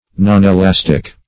Nonelastic \Non`e*las"tic\, a.